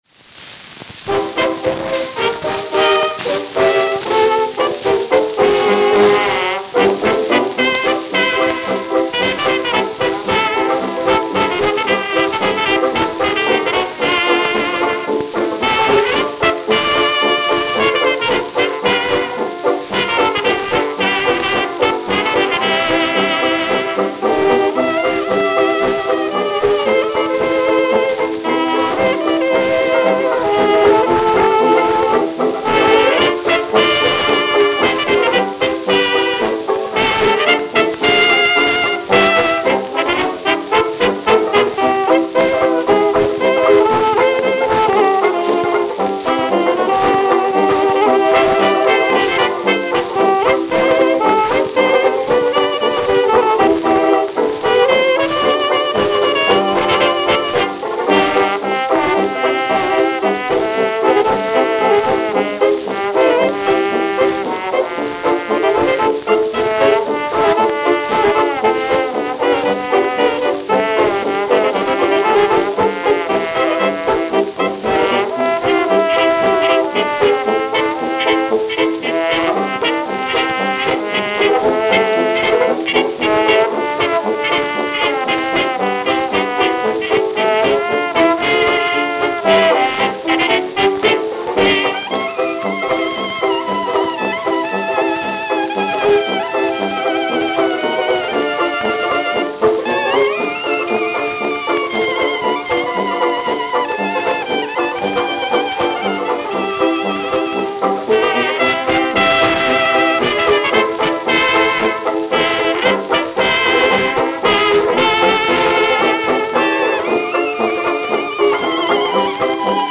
New York, New York New York, New York
Note: Worn at start.